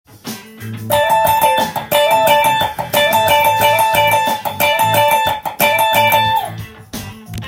全てAmキーの曲で使えるフレーズになります。
ハイポジションでAmペンタトニックスケールを和音に